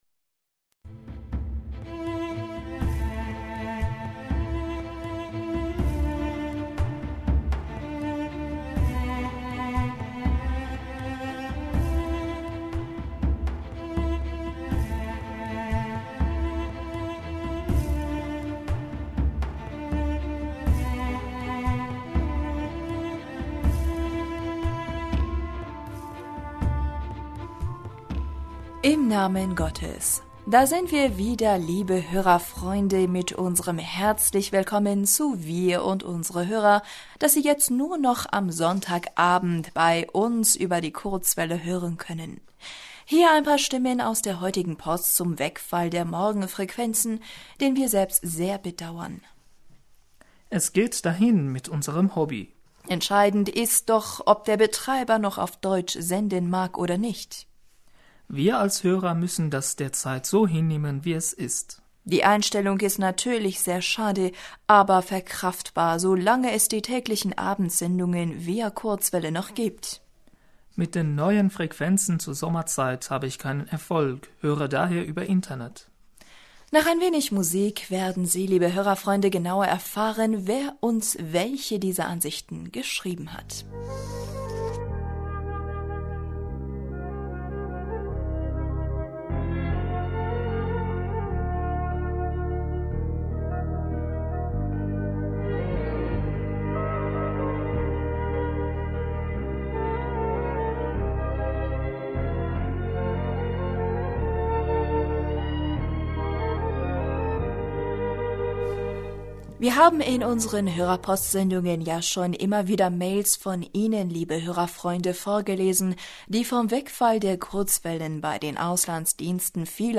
Hörerpostsendung am 10.April 2016 Bismillaher rahmaner rahim Da sind wir wieder liebe Hörerfreunde mit unserem